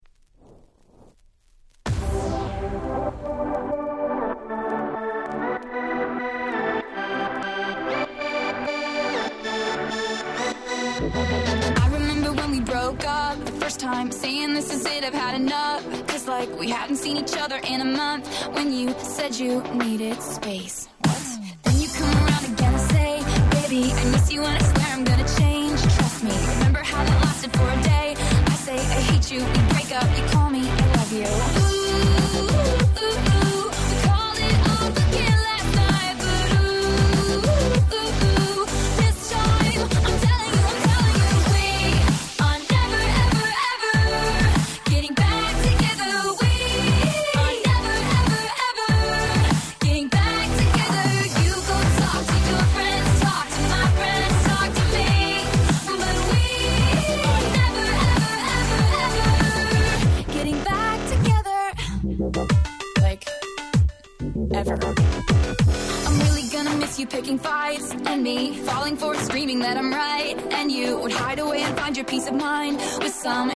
12' Super Hit R&B !!